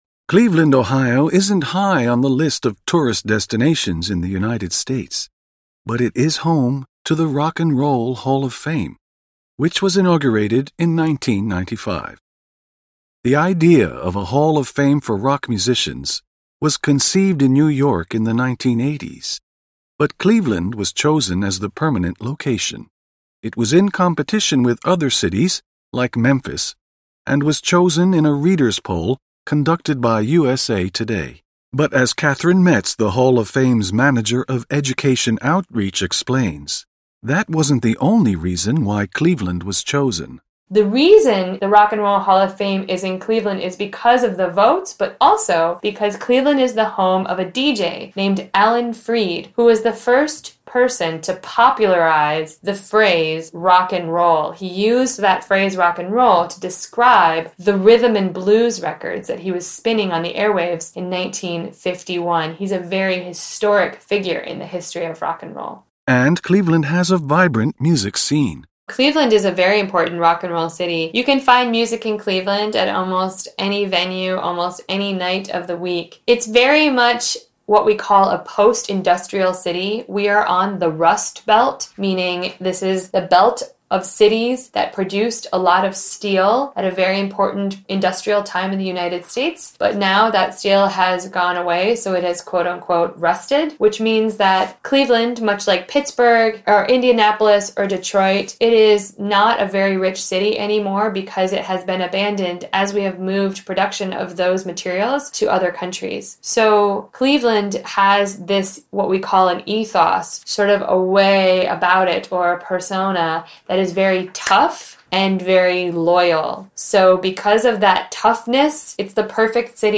INterview: cleveland rocks!